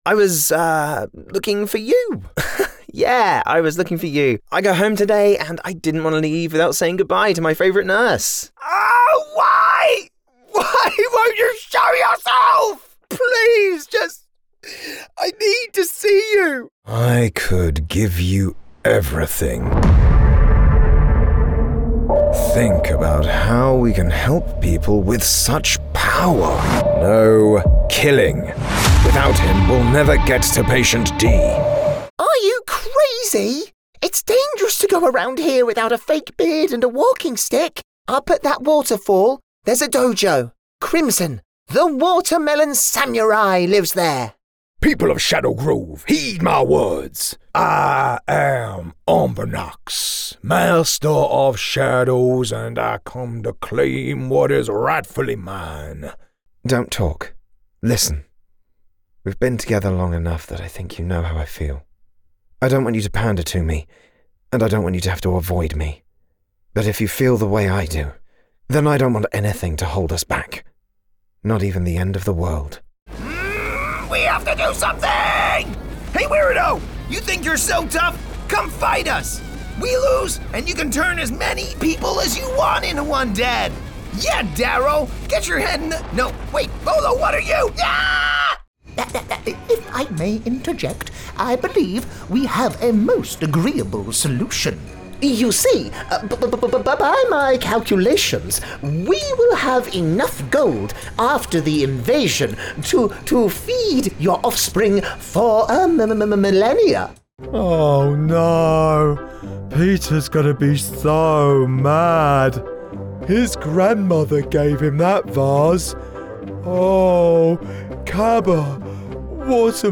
Engels (Brits)
Commercieel, Veelzijdig, Vriendelijk, Natuurlijk, Warm